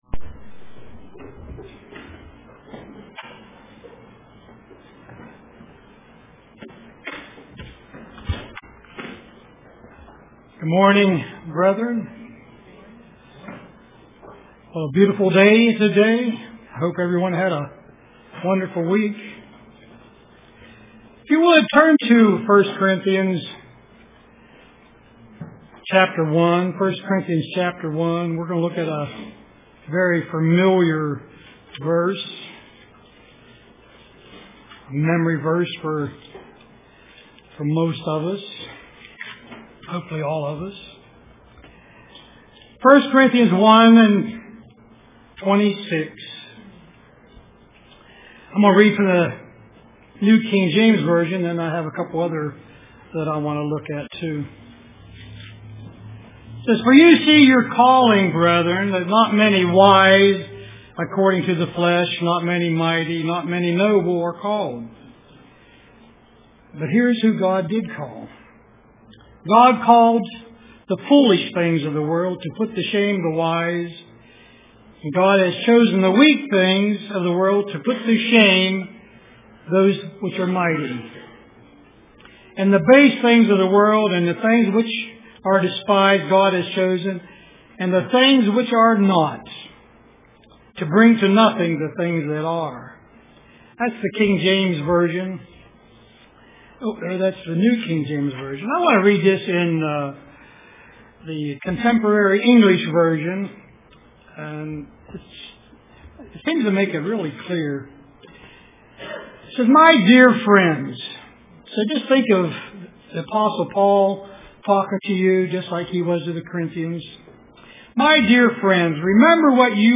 Print Our Calling UCG Sermon Studying the bible?